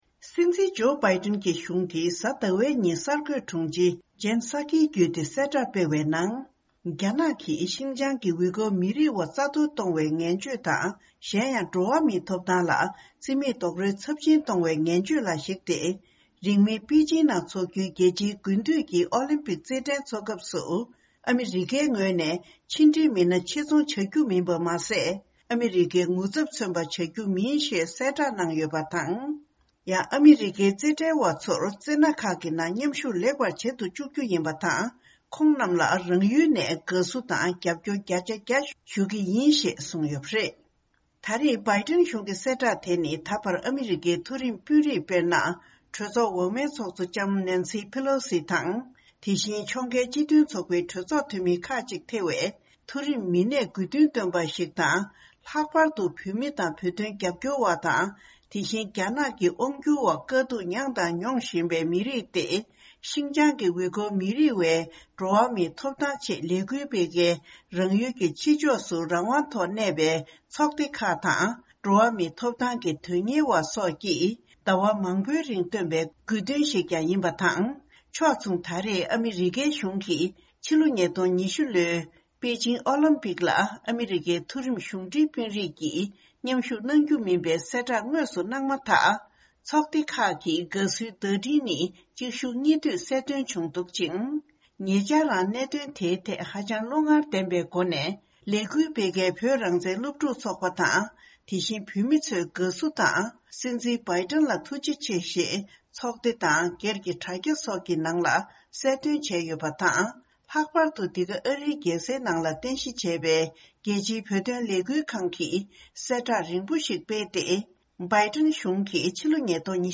ཕྱོགས་བསྒྲིགས་དང་སྙན་སྒྲོན་ཞུ་ཡི་རེད།།